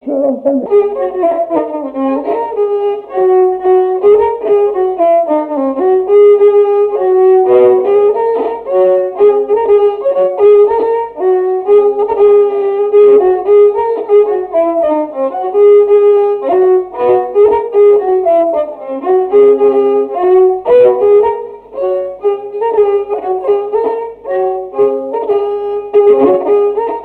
danse : ronde : grand'danse
répertoire d'un violoneux
Pièce musicale inédite